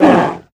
Minecraft Version Minecraft Version latest Latest Release | Latest Snapshot latest / assets / minecraft / sounds / mob / polarbear / hurt2.ogg Compare With Compare With Latest Release | Latest Snapshot
hurt2.ogg